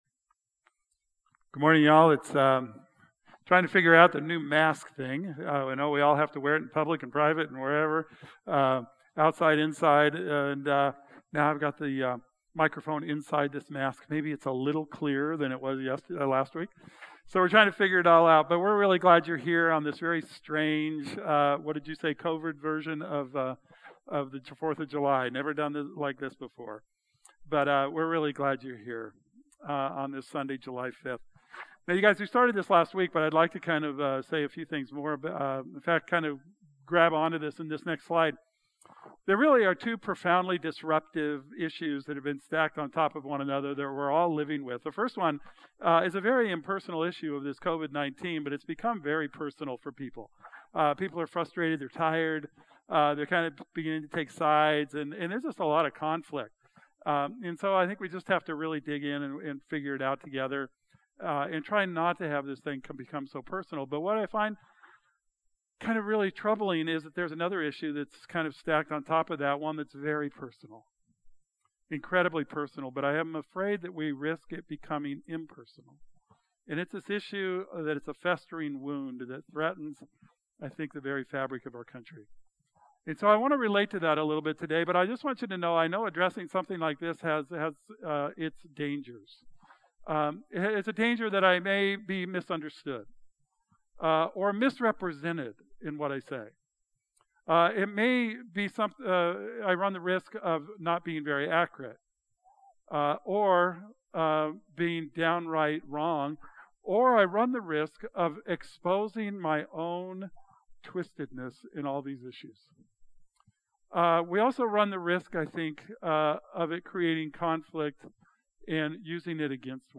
Sunday Service – July 5, 2020